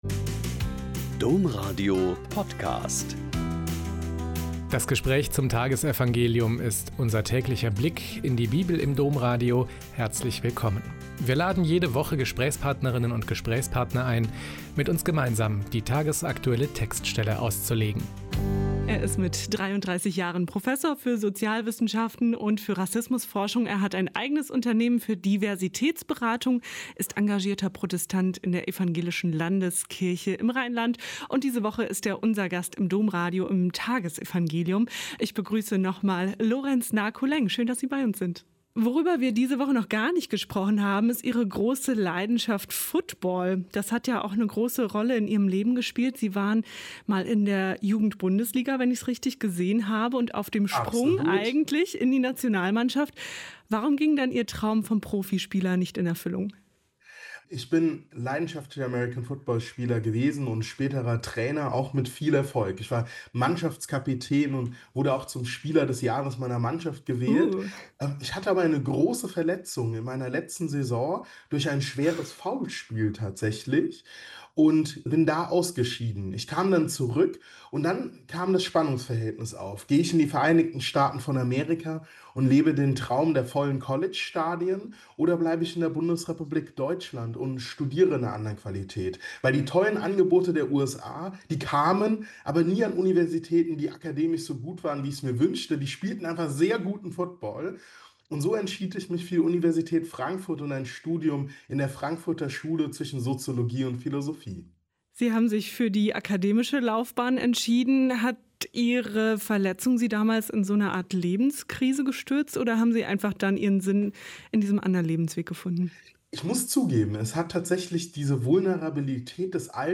Lk 6,1-5 - Gespräch